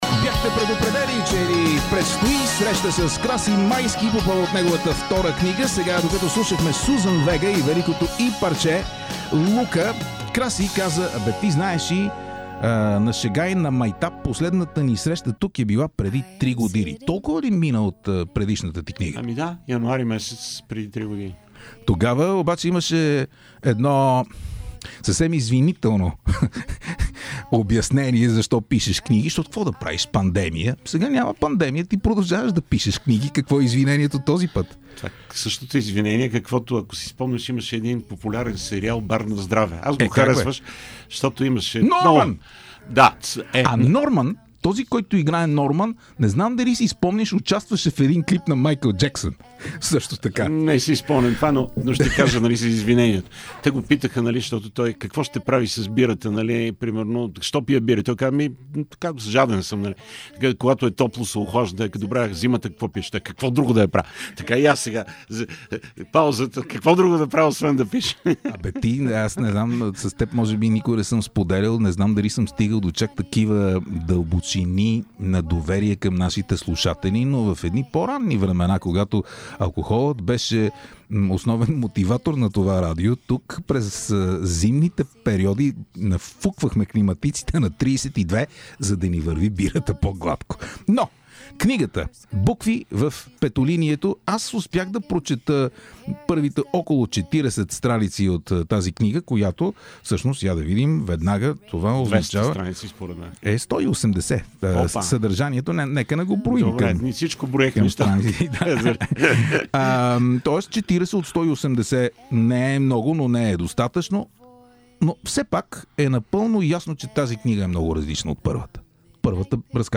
Разговорът ни с него е спонтан и затова пълен с обрати!